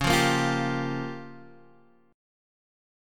C#6b5 chord